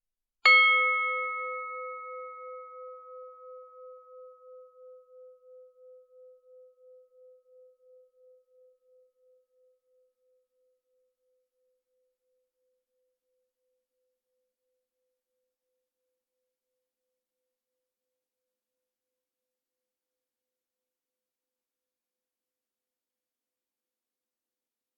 Steel Bell
bell chime ding percussion ring steel stereo xy sound effect free sound royalty free Sound Effects